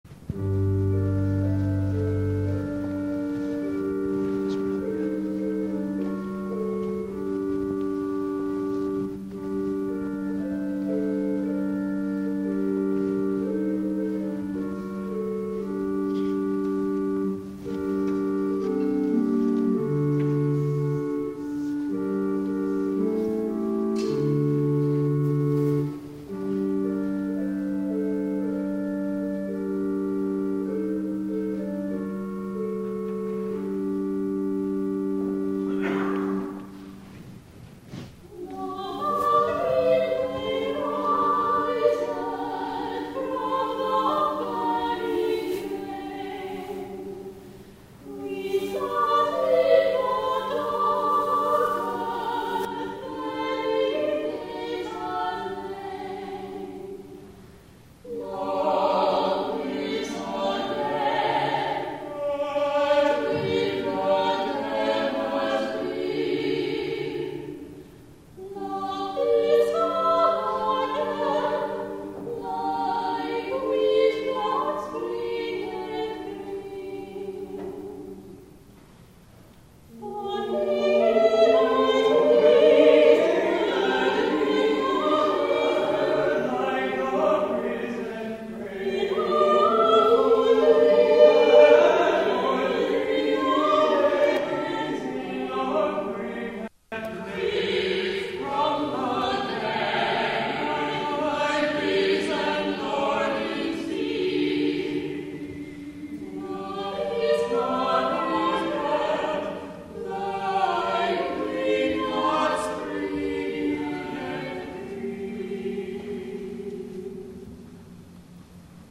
THE OFFERTORY